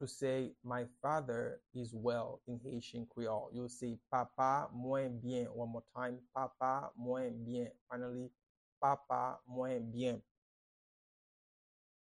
Pronunciation and Transcript:
My-father-is-well-in-Haitian-Creole-Papa-mwen-byen-pronunciation-by-a-Haitian-Creole-teacher.mp3